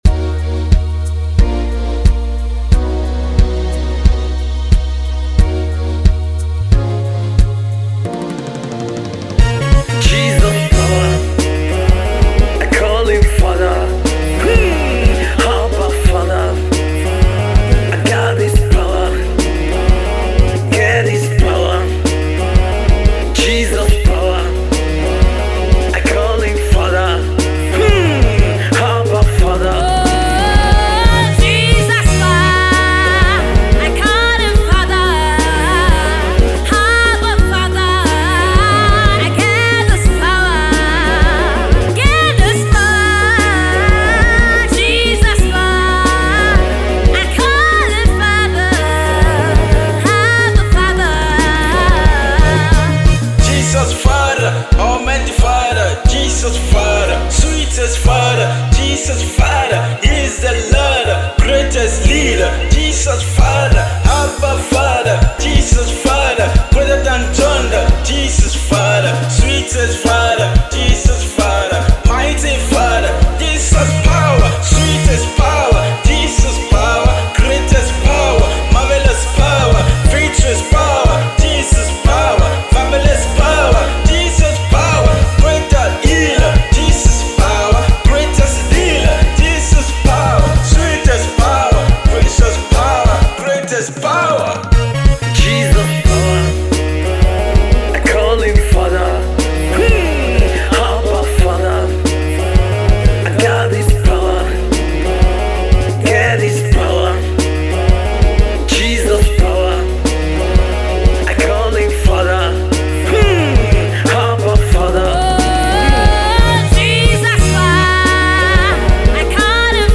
high spiritual song